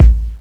INSKICK02 -L.wav